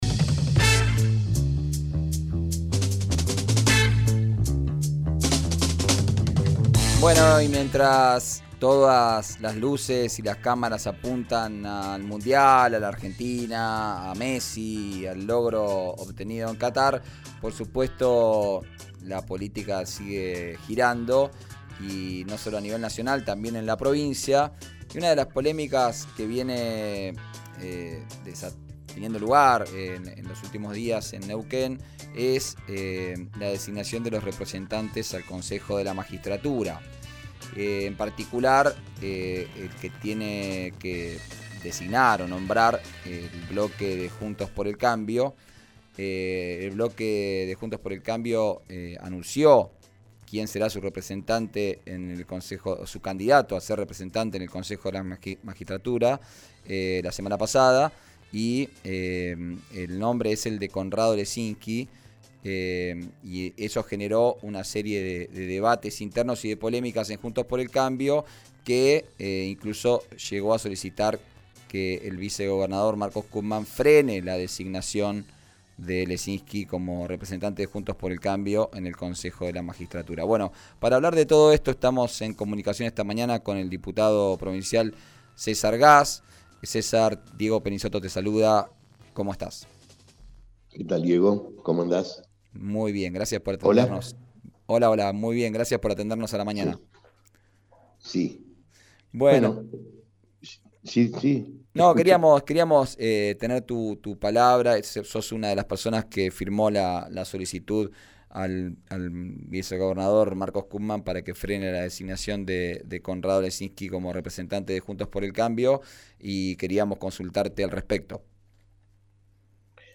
El diputado radical habló en 'Arranquemos', por RÍO NEGRO RADIO. Defendió la candidatura de Pablo Cervi: 'es el que mejor representa a Juntos'.